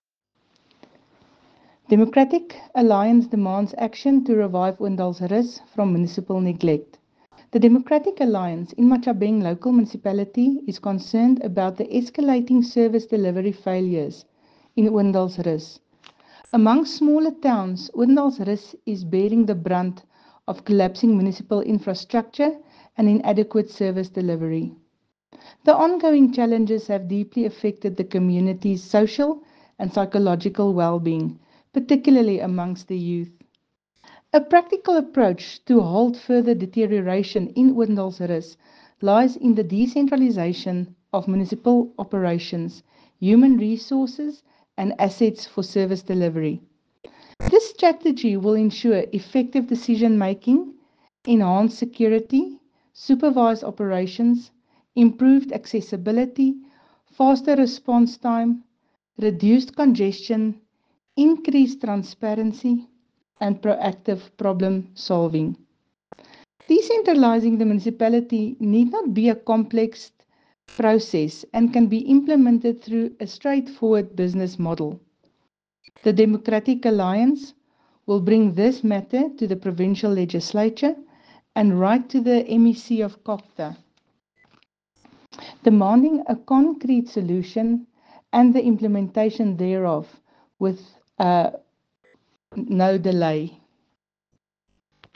Afrikaans soundbites by Cllr Jessica Nel and